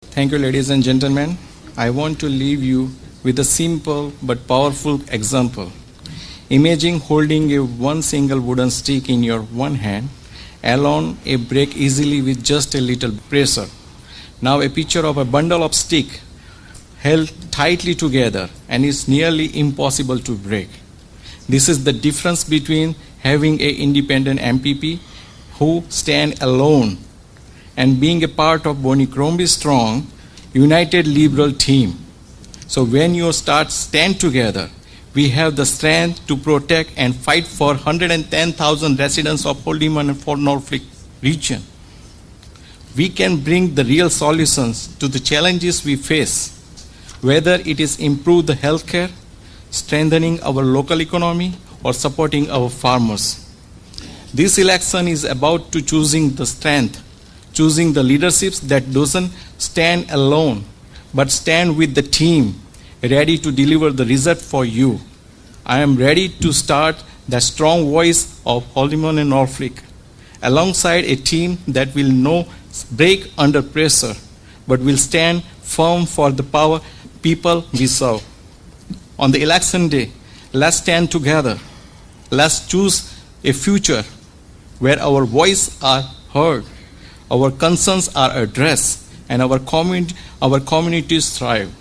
It seemed fitting that, with only one debate featuring a majority of the candidates being held, that we highlighted their closing statements from that night on the final day of the election campaign.